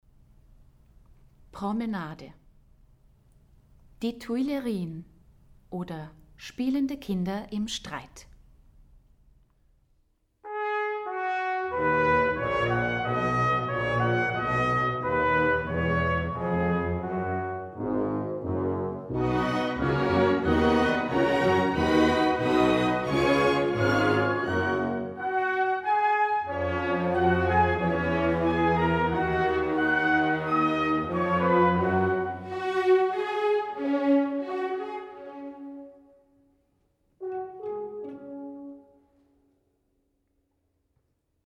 Hör dir die Musik „Bilder einer Ausstellung“ von Modest Mussorgsky, gespielt von der Philharmonie Salzburg an.